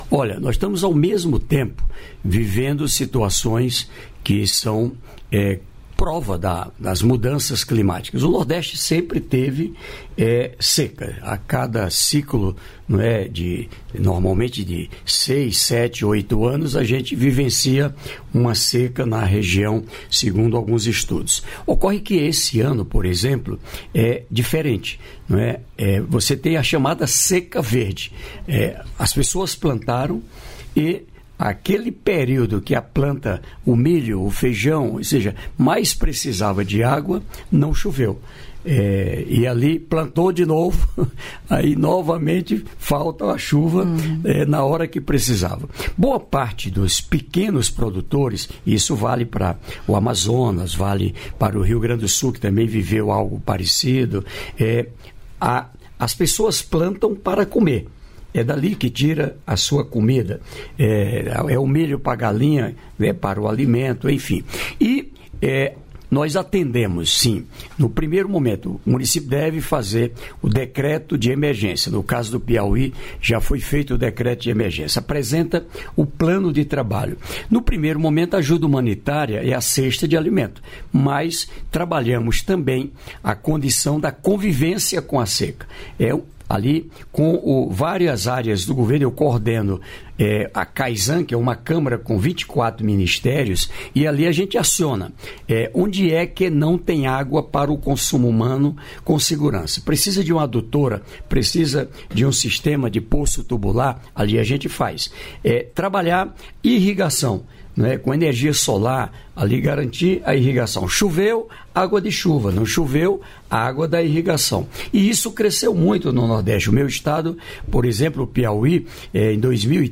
Trecho da participação do ministro do Desenvolvimento e Assistência Social, Família e Combate à Fome, Wellington Dias, no programa "Bom Dia, Ministro" desta quinta-feira (7), nos estúdios da EBC em Brasília (DF).